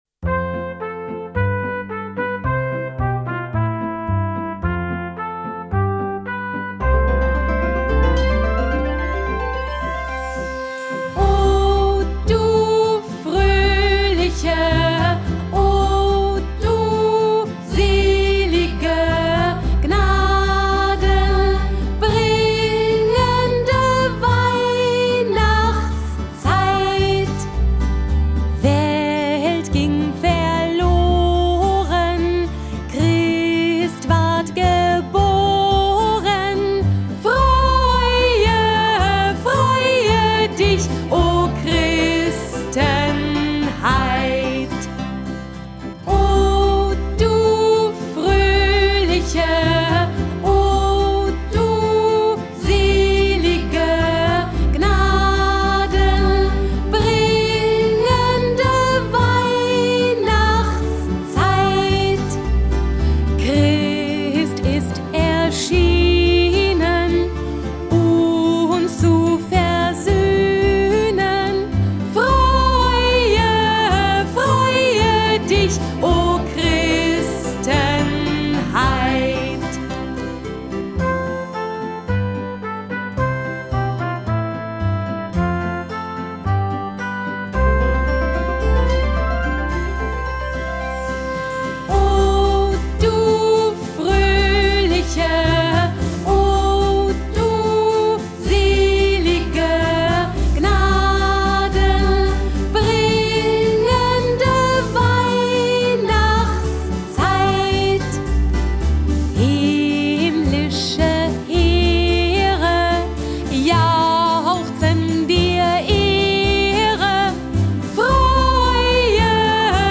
Weihnachtslieder